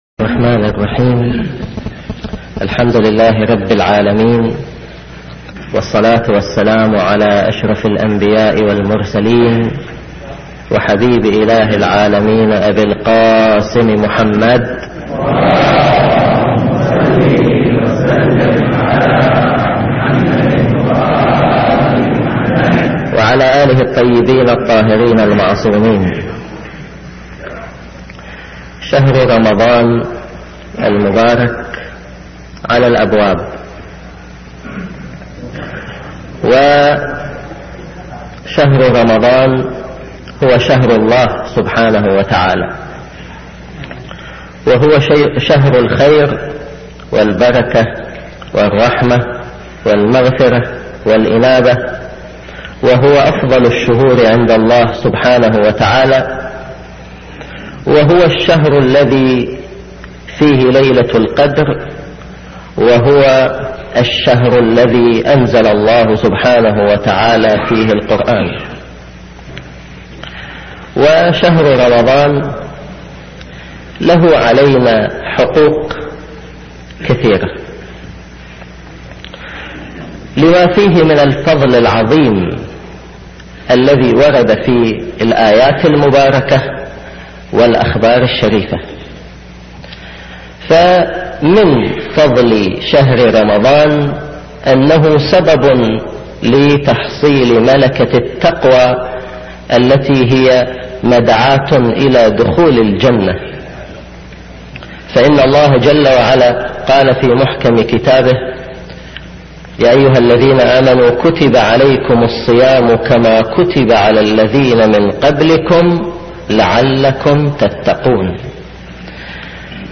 محاضرات عامة